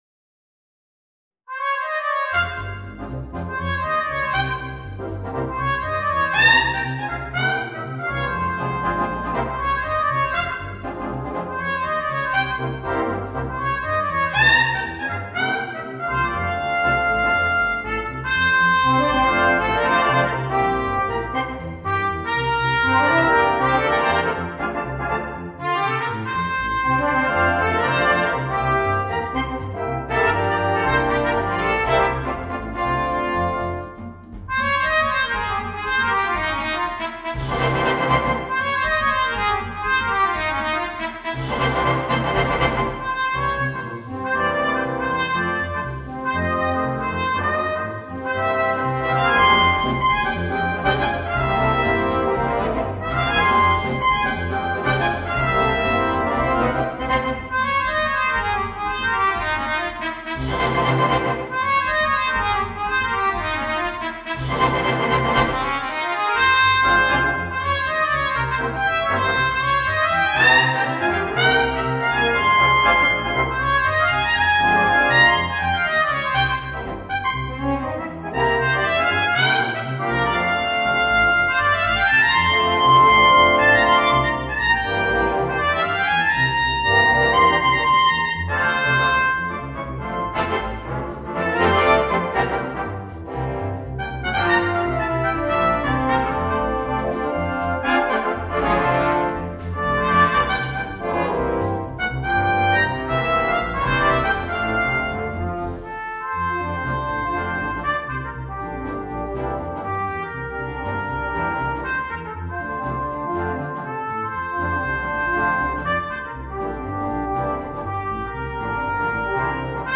Voicing: Cornet and Brass Band